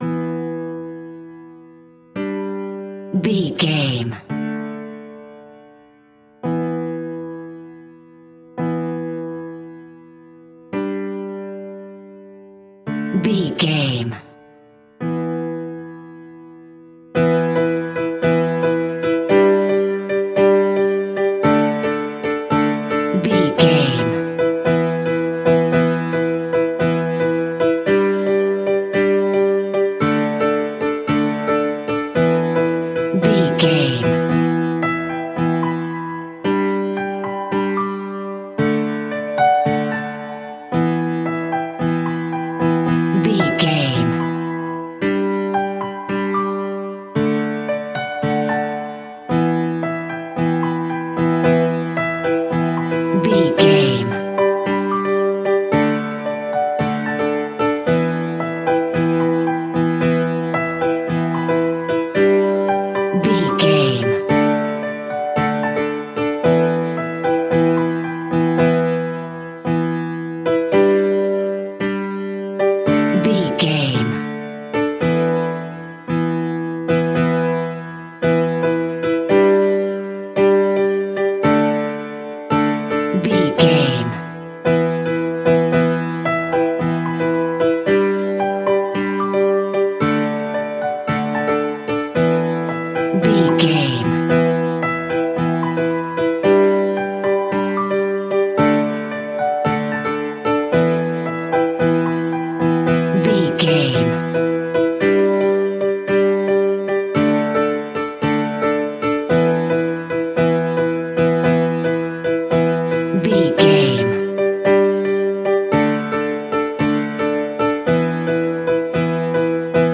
Aeolian/Minor
Exotic